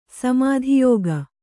♪ samādhi yōga